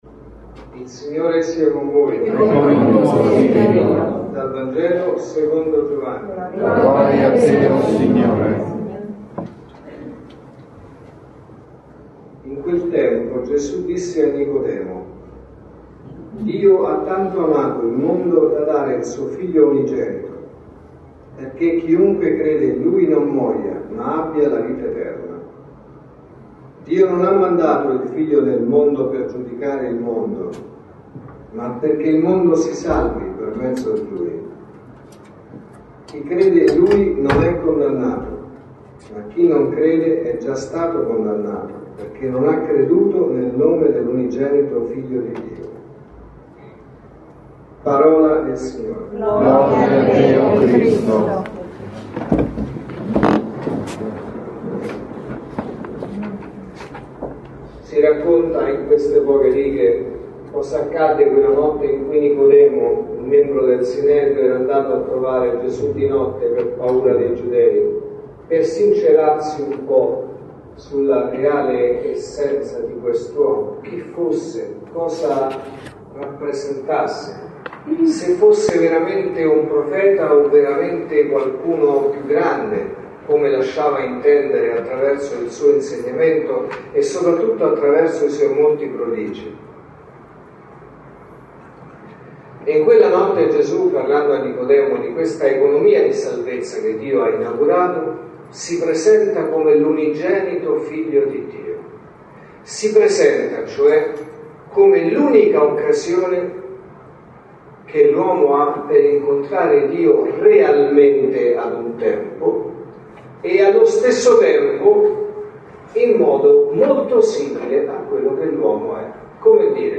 Omelie Messa della mattina